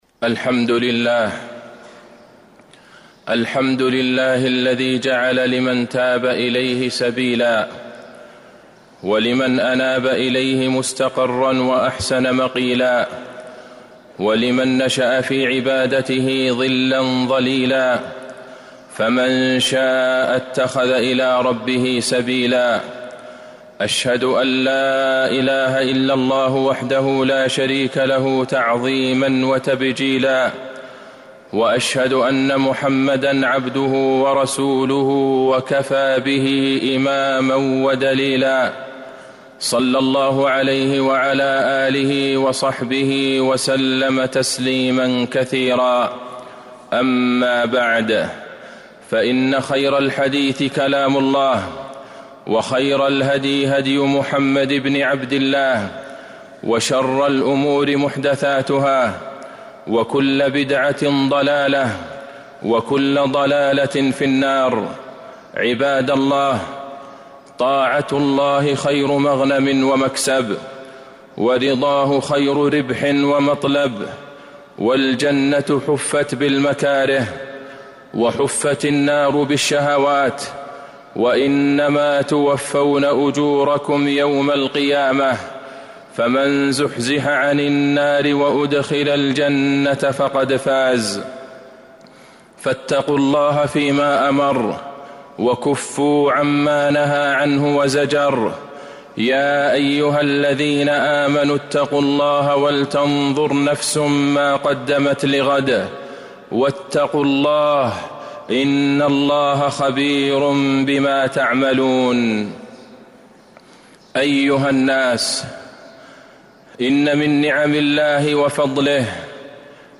المدينة: استقبلوا رمضان - عبد الله بن عبد الرحمن البعيجان (صوت - جودة عالية